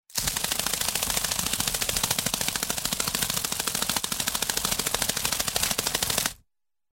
Здесь вы найдёте разные варианты записей: от нежного шелеста до интенсивного жужжания во время быстрого полёта.
Звук крыльев стрекозы